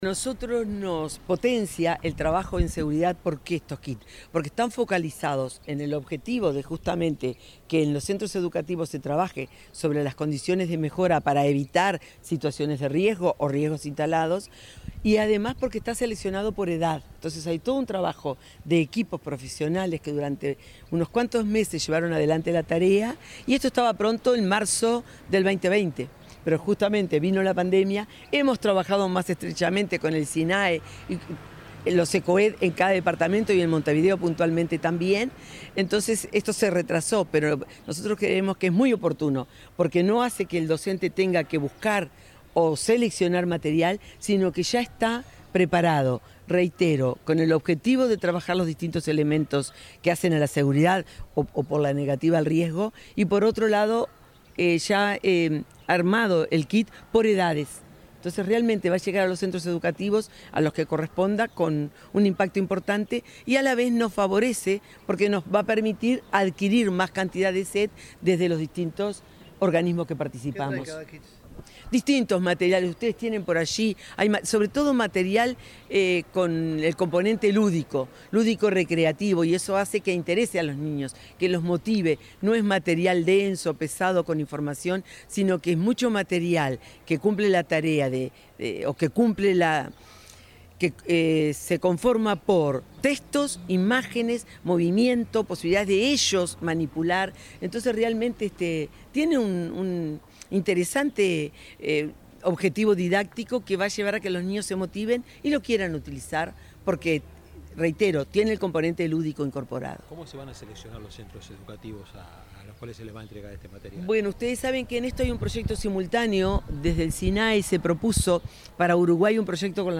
Declaraciones de la directora general de Educación Primaria, Graciela Fabeyro
Este jueves 17 en el Centro Coordinador de Emergencias Departamentales (Cecoed) de Montevideo, el Sistema Nacional de Emergencias (Sinae) y Unicef entregaron el primer kit de materiales didácticos para la recreación educativa en situaciones de emergencia con niños y adolescentes. Luego la directora general de Educación Inicial y Primaria, Graciela Fabeyro, dialogó con la prensa.